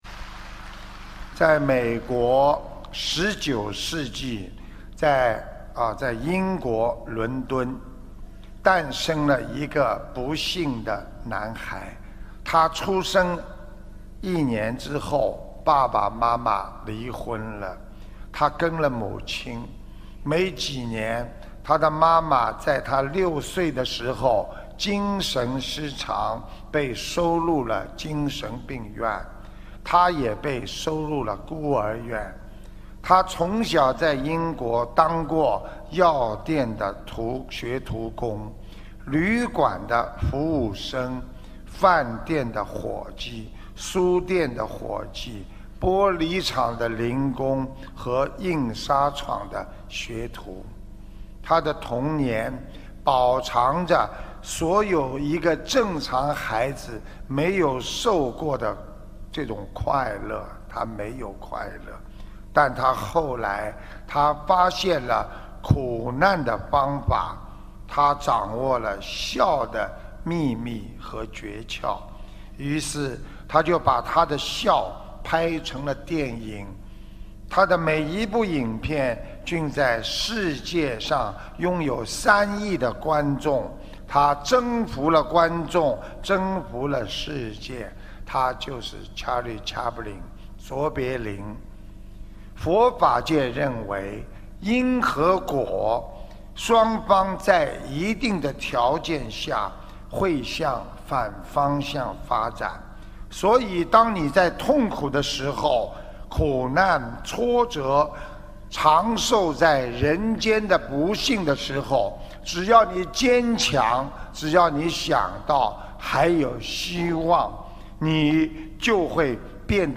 澳大利亚墨尔本